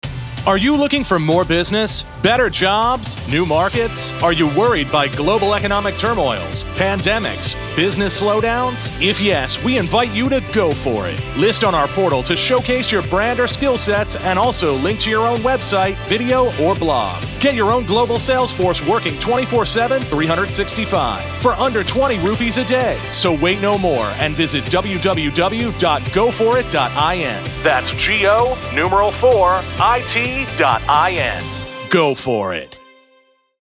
GO4IT Radio Spot.mp3